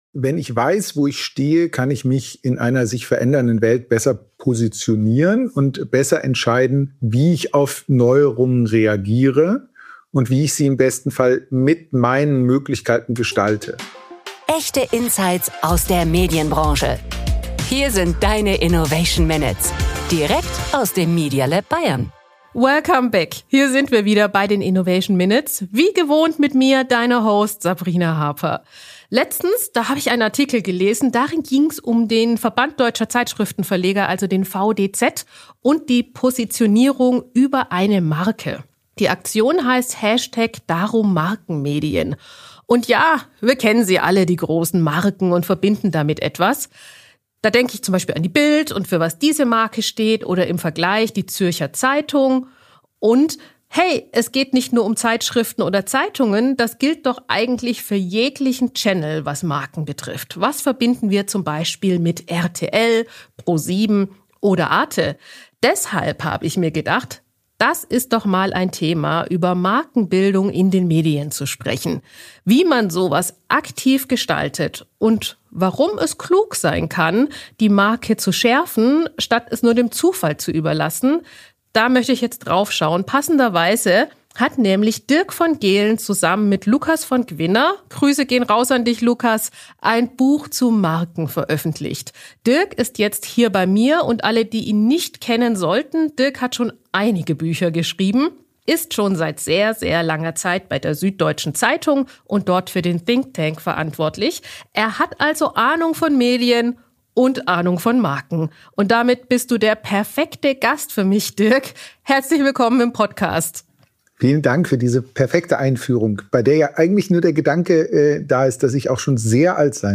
Markenbildung im Journalismus: Können Journalismus und Markenbildung wirklich eine Einheit sein. Ein kritisches Gespräch im Podcast